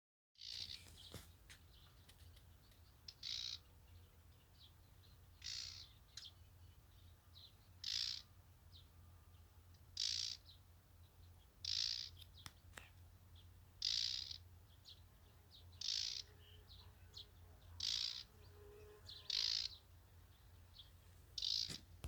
болотная камышевка, Acrocephalus palustris
СтатусВзволнованное поведение или крики